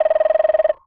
cartoon_electronic_computer_code_07.wav